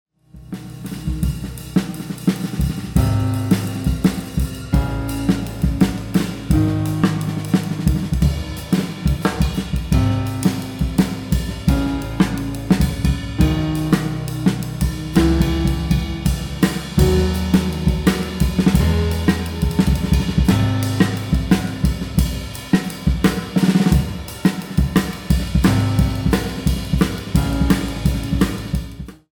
Yamaha Motif keyboard, Drums